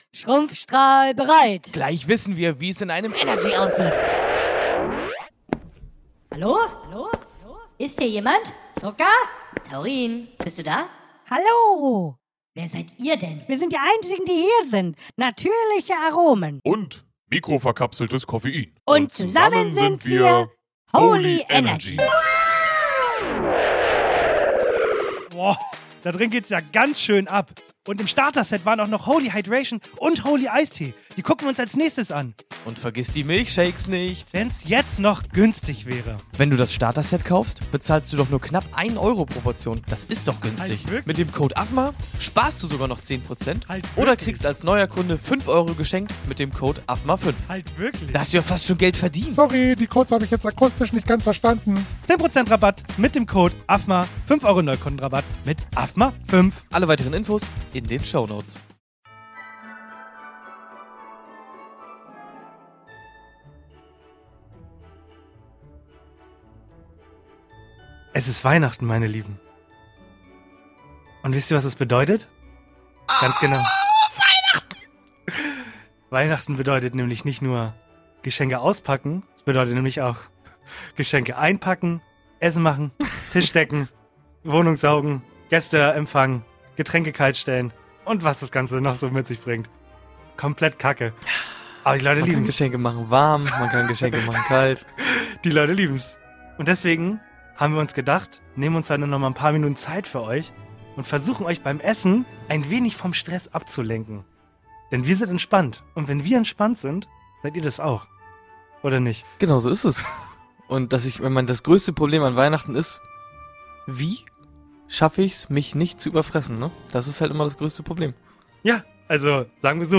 Hier gibt's die ganzen Gags aus einer Folge komprimiert in einer kurzen Superfolge!! Unter Anderem rufen wir einen Nicht-Fan an und fragen, was wir falsch gemacht haben :(( Quiz und gute Laune und YEAH gibt's natürlich auch, also Airpod rein (Scheiß Bonze) und Folge anmachen, ohne das die Familie was mitkriegt, frohe Weihnachten!